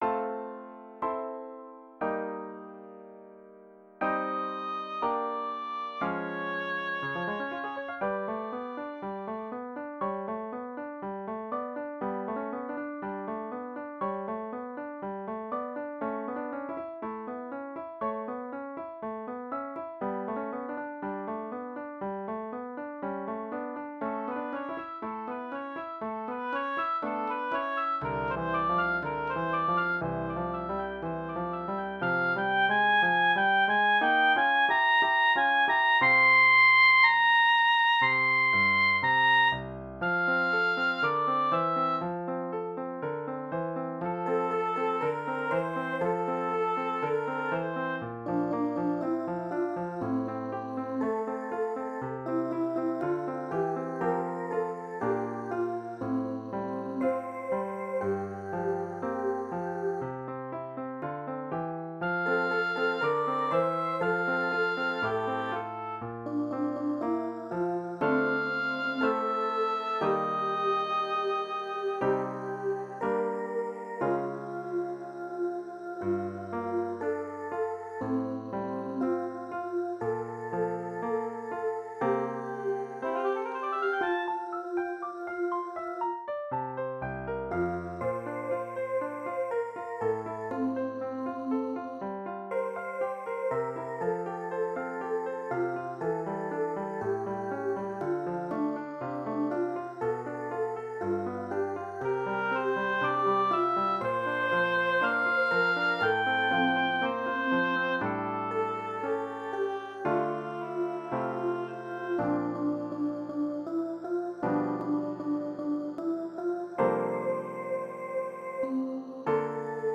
Pit Band Orchestration